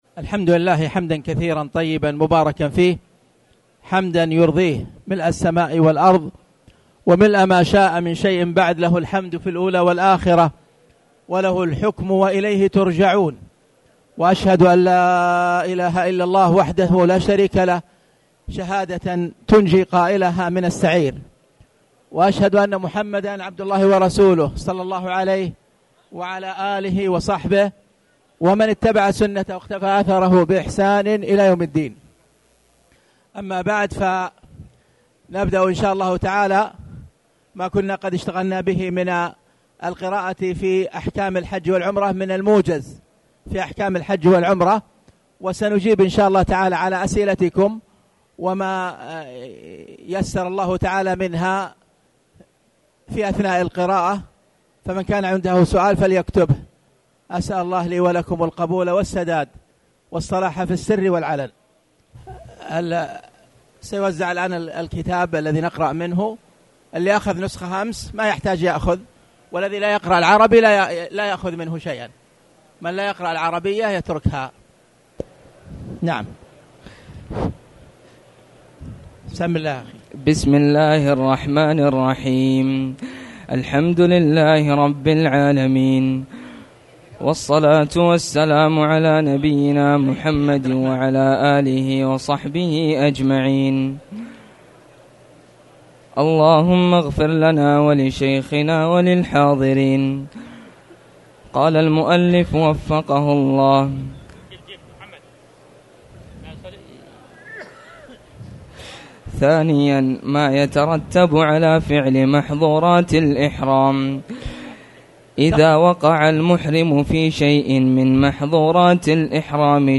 تاريخ النشر ٦ ذو الحجة ١٤٣٨ هـ المكان: المسجد الحرام الشيخ: خالد بن عبدالله المصلح خالد بن عبدالله المصلح محظورات الإحرام